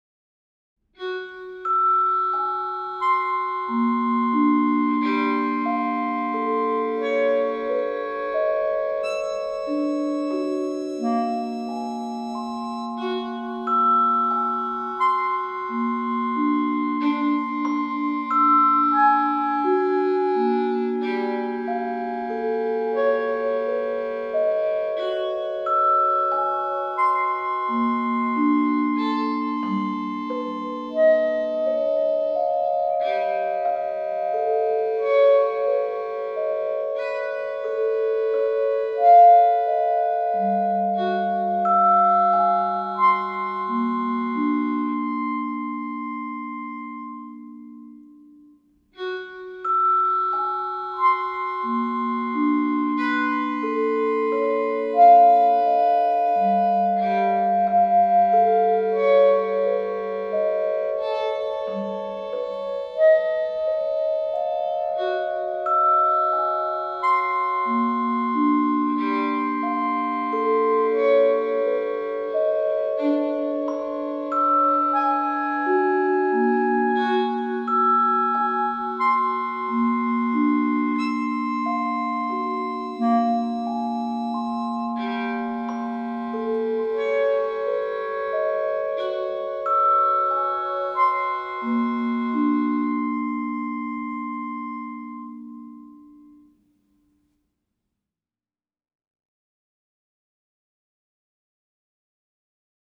violin
clarinet
vibraphone
celesta